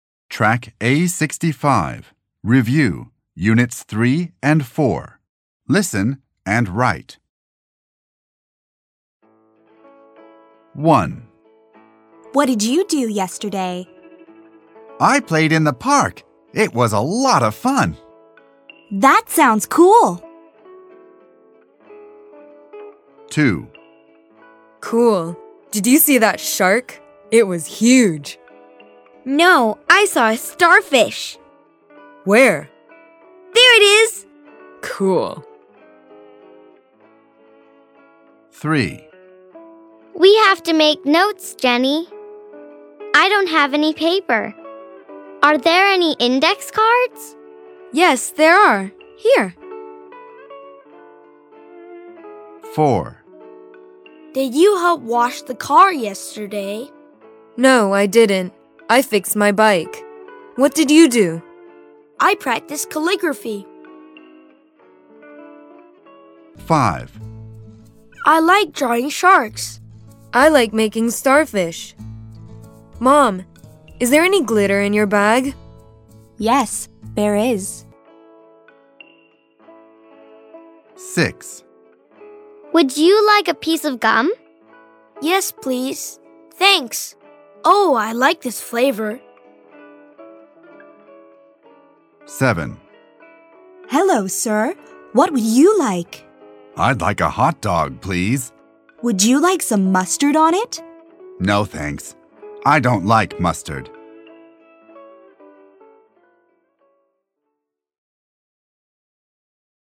این مجموعه با لهجه ی  امریکن و توسط نویسندگانی چون Beat Eisele, Catherine Yang Eisele, Stephen M. Hanlon, Rebecca York Hanlon به رشته تحریر درآمده است و همچنین دارای سطح بندی از مبتدی تا پیشرفته می باشد و سراسر مملو از داستان های جذاب با کاراکتر های دوست داشتنی می باشد که یادگیری و آموزش زبان انگلیسی را برای کودکان ساده و لذت بخش تر خواهد کرد.